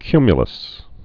(kymyə-ləs)